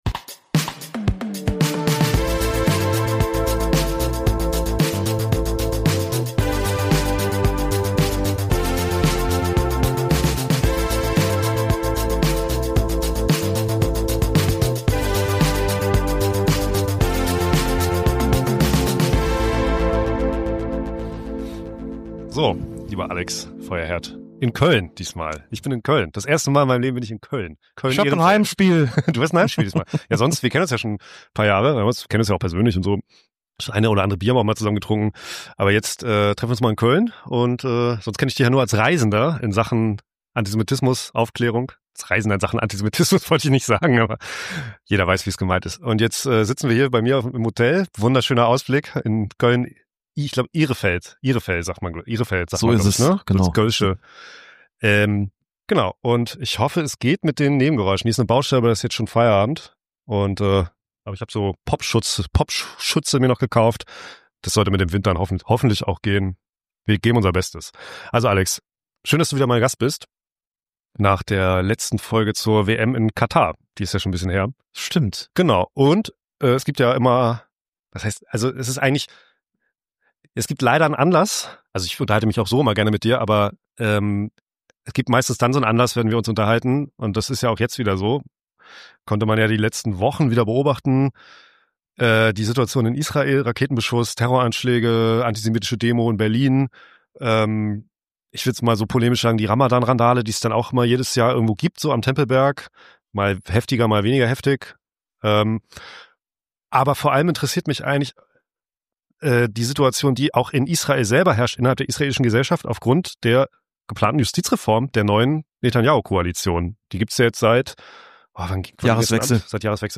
Beschreibung vor 3 Jahren Frisch auf dem Balkon meines Hotelzimmers in Köln-Ehrenfeld recorded